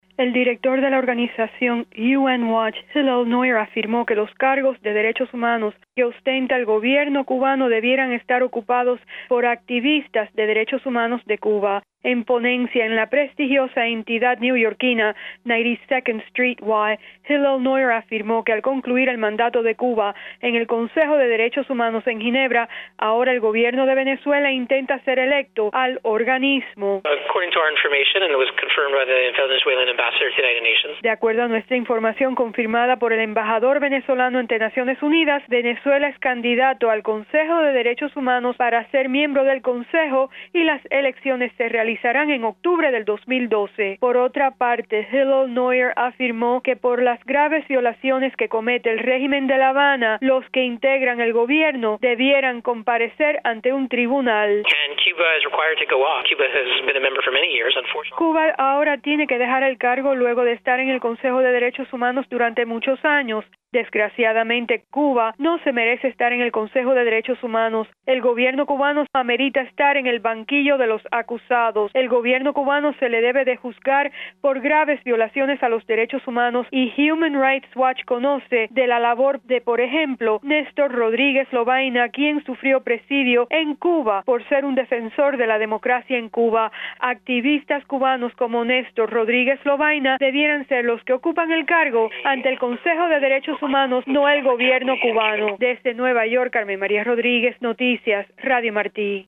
Declaraciones de alto funcionario de la ONU